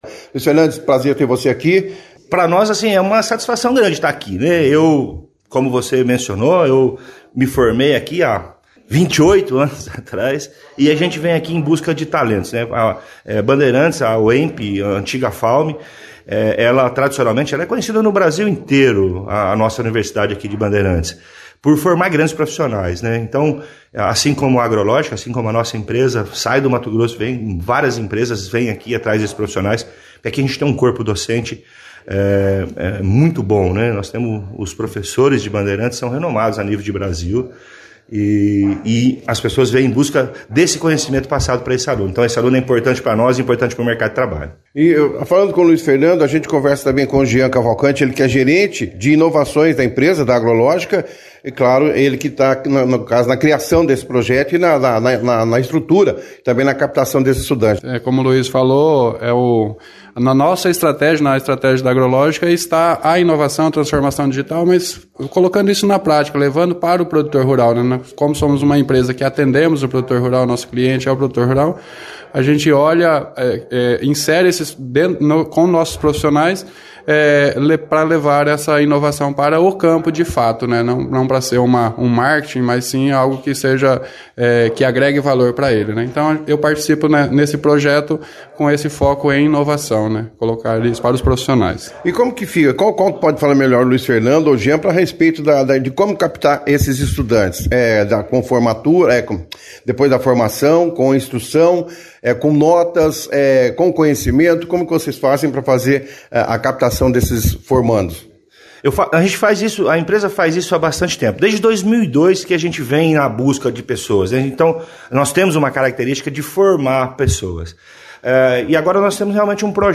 participaram da 2ª edição do jornal Operação Cidade desta quarta-feira, 23/02, falando sobre o projeto.